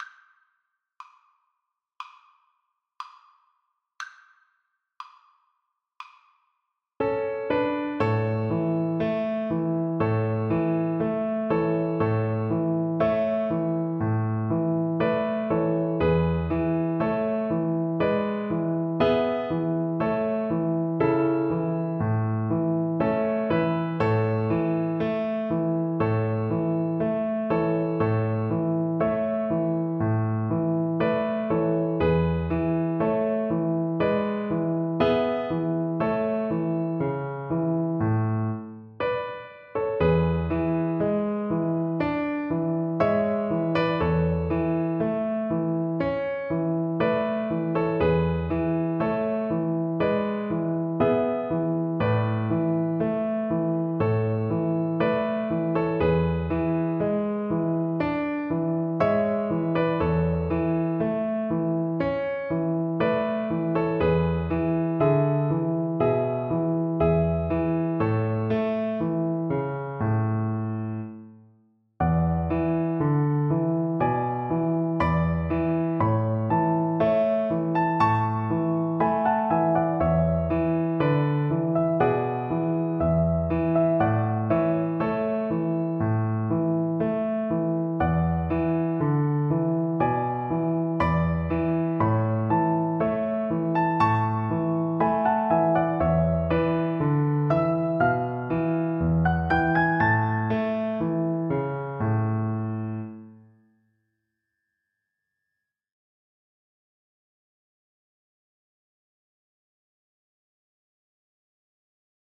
Moderato
Classical (View more Classical Violin Music)
Neapolitan Songs for Violin